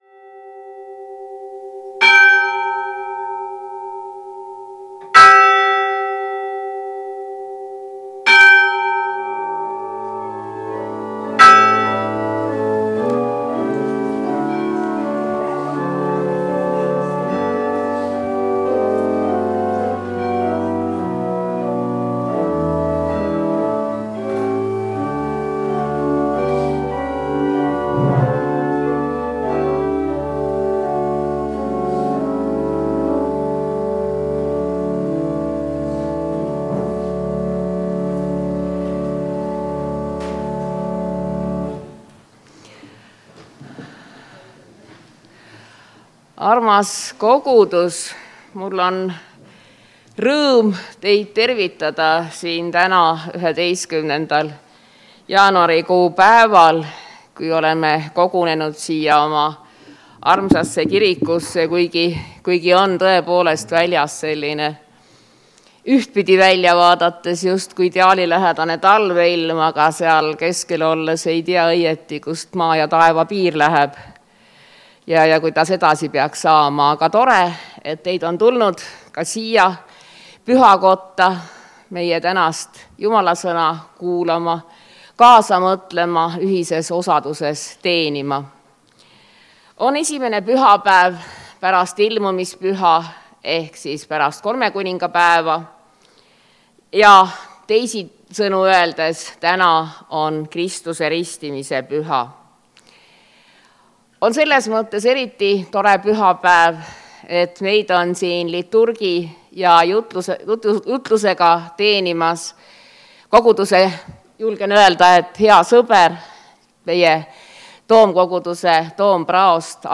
Jumalateenistus 11. jaanuar 2026